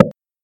soft-hitclap.ogg